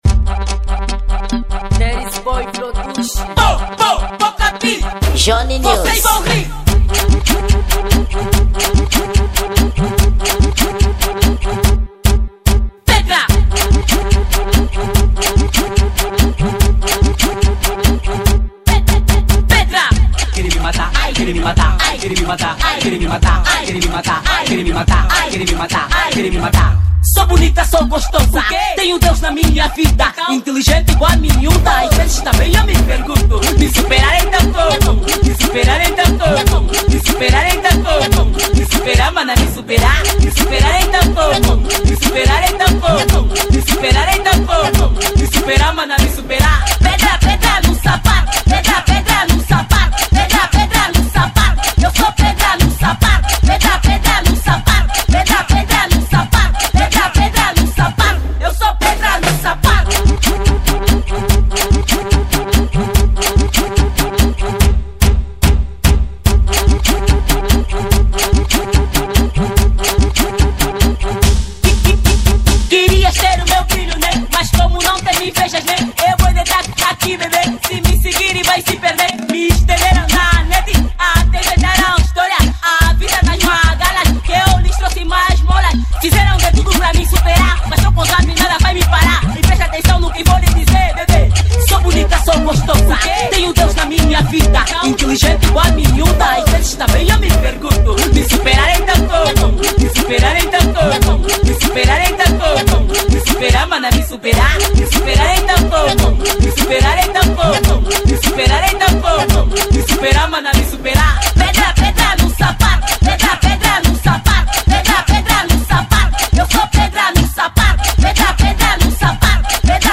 Kuduro
Gênero: Funk Duro